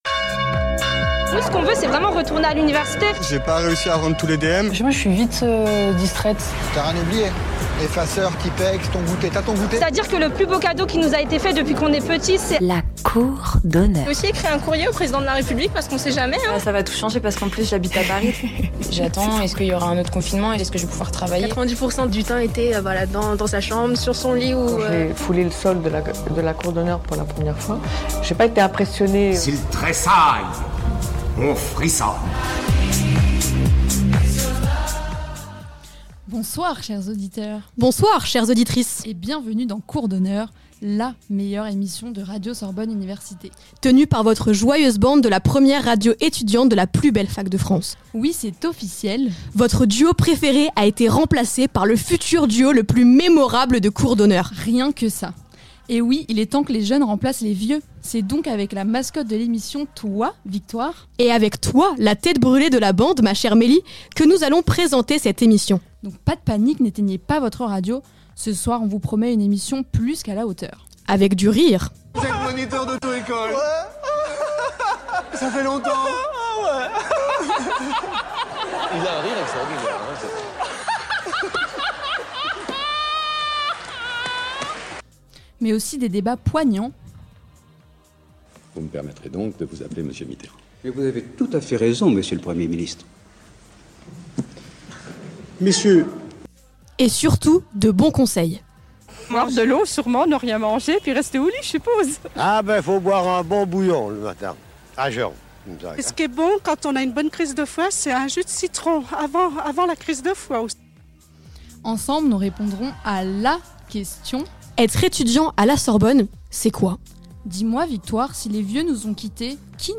Un jeudi sur deux sur Radio Sorbonne Université.